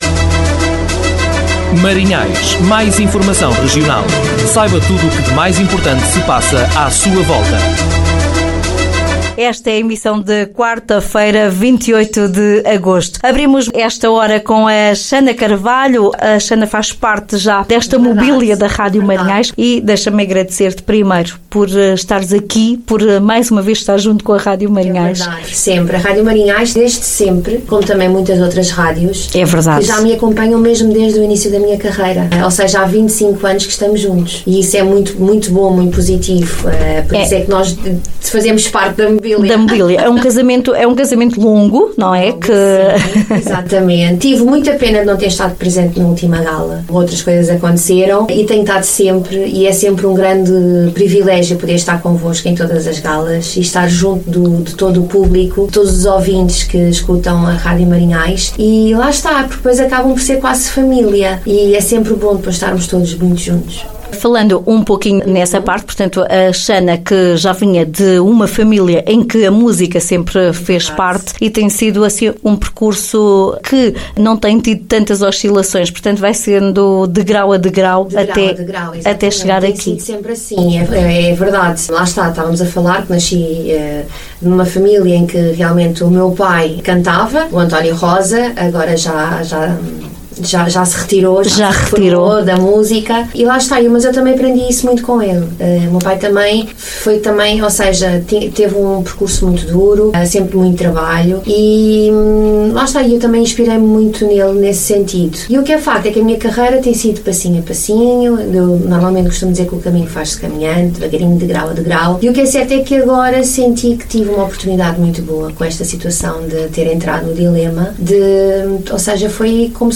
RM Entrevista